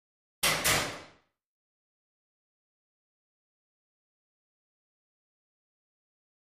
Locker Room; Metal Locker Door Slam With Double Latch.